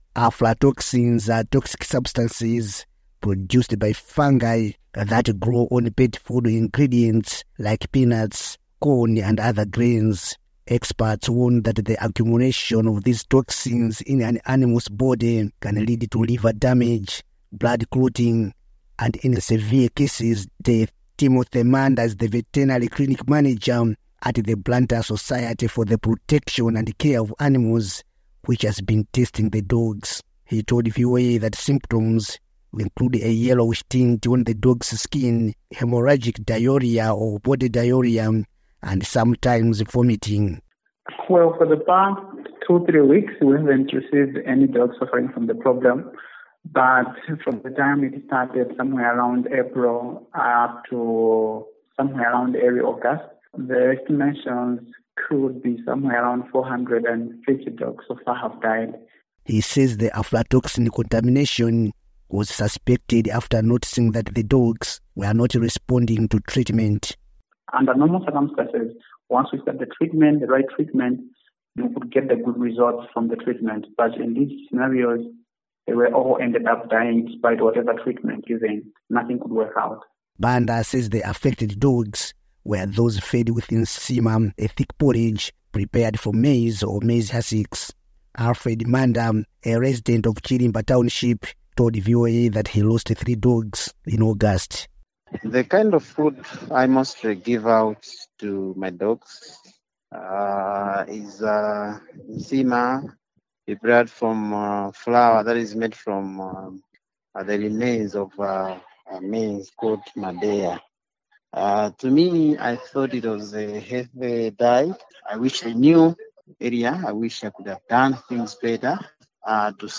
reports from Blantyre